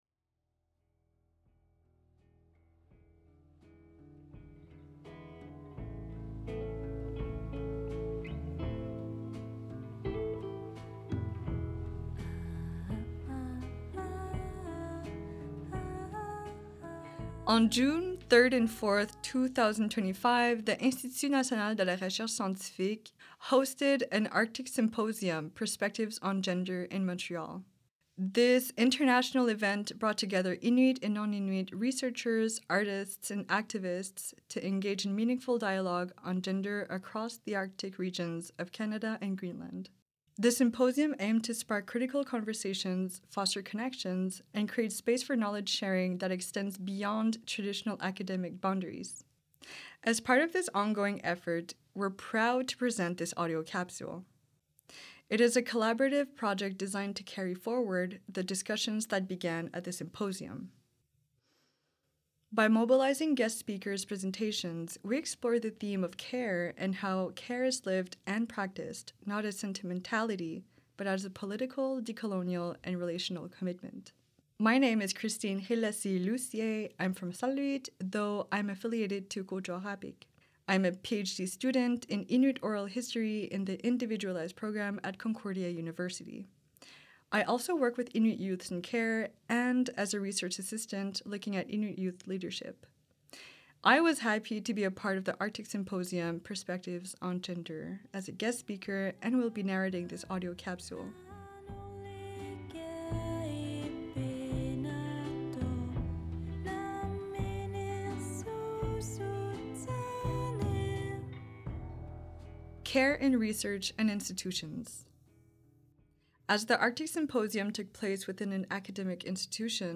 This episode is the result of a collaborative effort between our guest speakers and the symposium’s organizing committee.
This audio capsule revisits key segments from the 2025 international Arctic Symposium: Perspectives on Gender, highlighting ongoing reflections on care as both practice and politics.